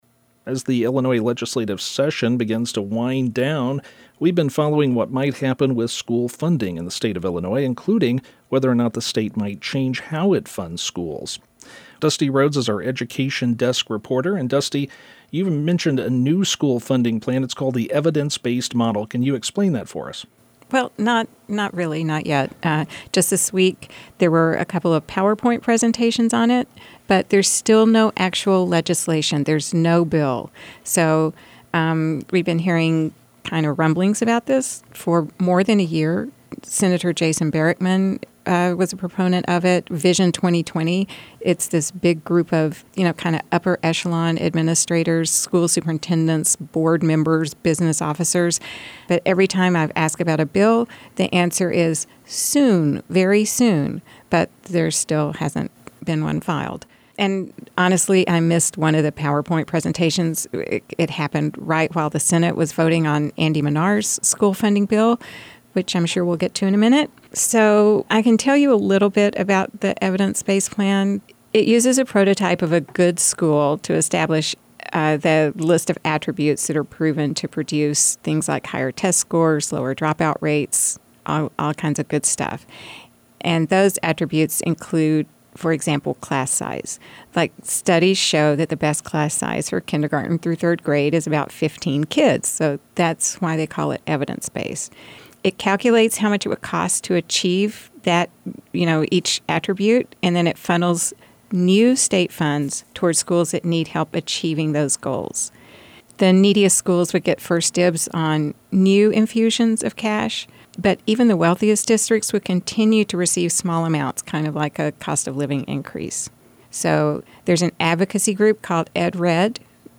Here's a longer discussion of the various school funding formulas.